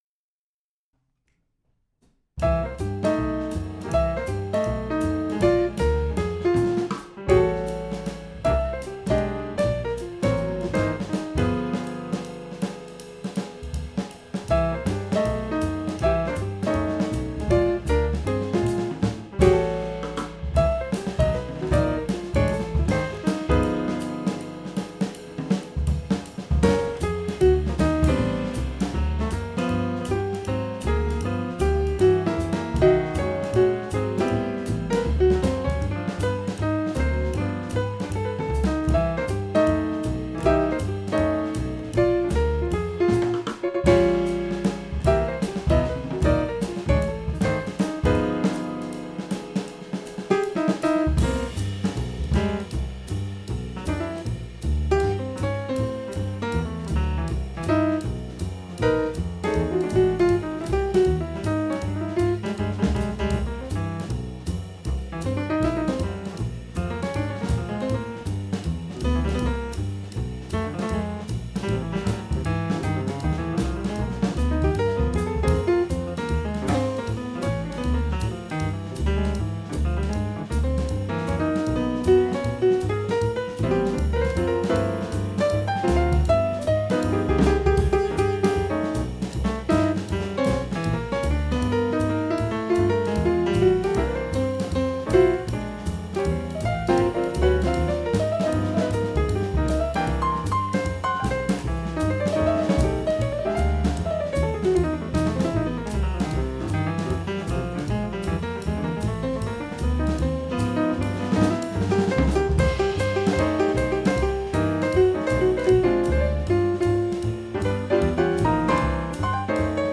Piano
Contrebasse
Batterie